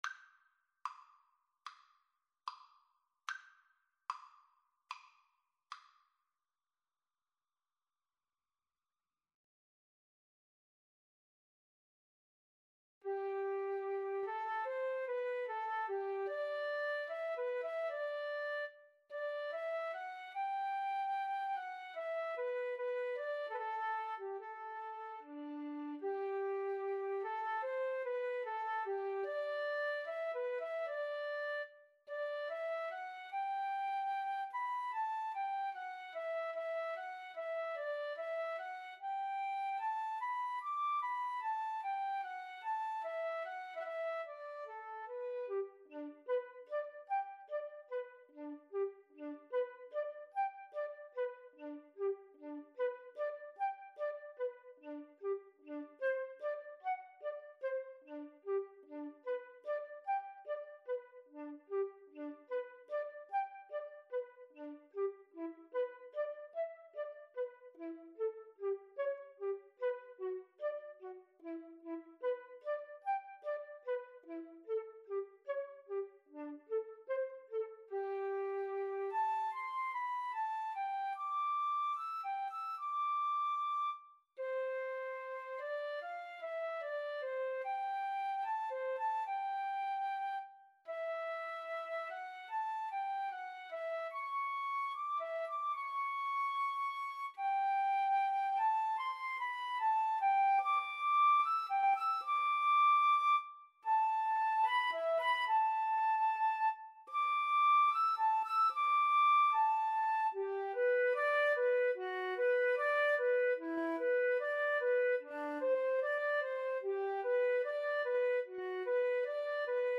G major (Sounding Pitch) (View more G major Music for Flute Duet )
Andantino quasi allegretto ( = 74) (View more music marked Andantino)
Flute Duet  (View more Intermediate Flute Duet Music)
Classical (View more Classical Flute Duet Music)